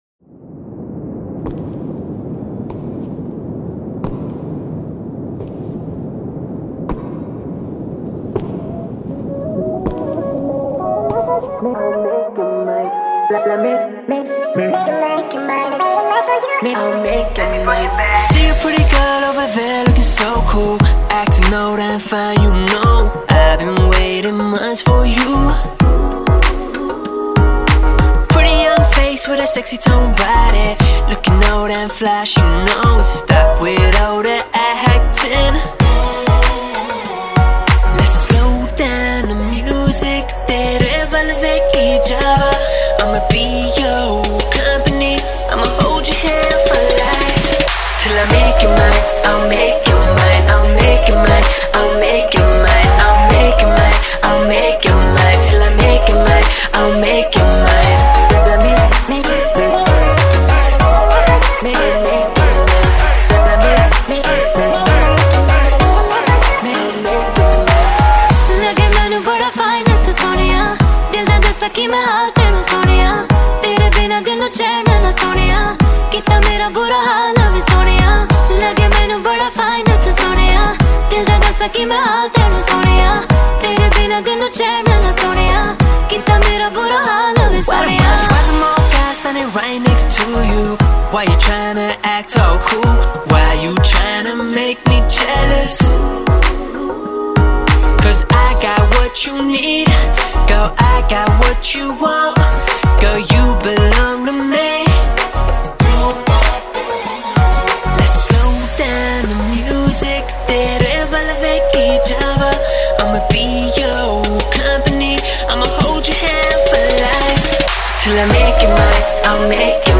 Single Indian Pop